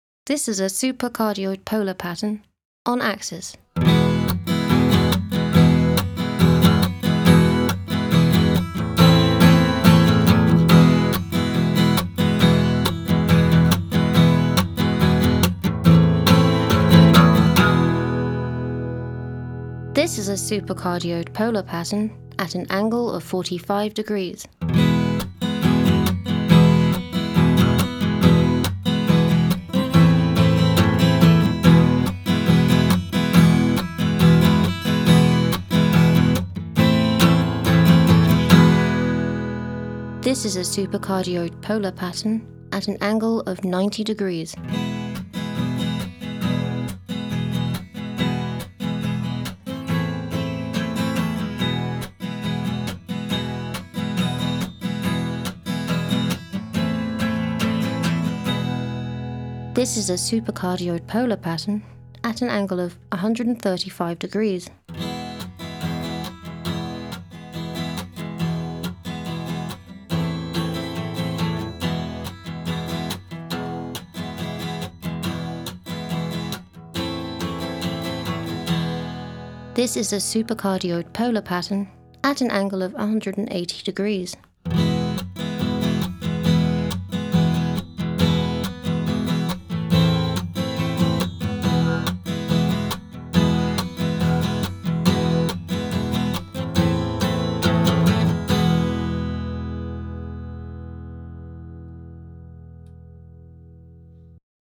Supercardioïde:
En lisant le fichier audio 3, vous remarquerez que le son provenant des côtés (135 degrés) est presque entièrement atténué. Le son en provenance de l’arrière est enregistré mais moins fort que celui en provenance de l’avant de la membrane.
3.-Supercardioid.mp3